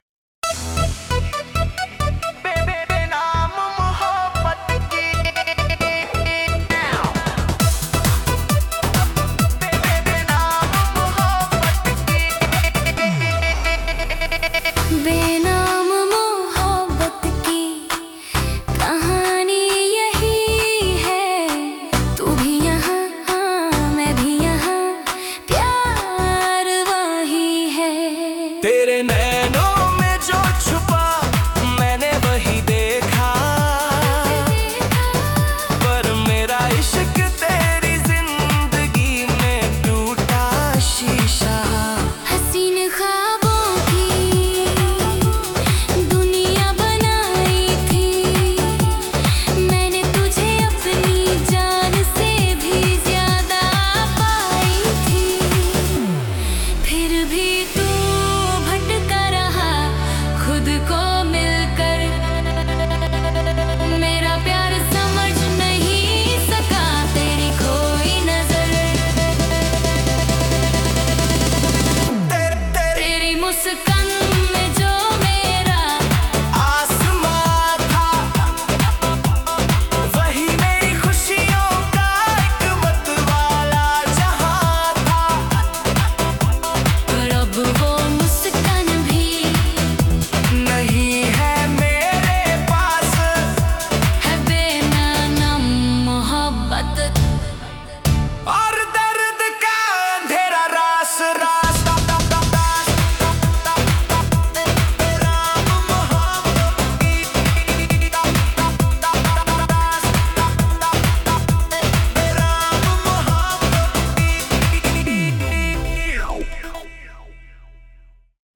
Hindi Bubblegum Dance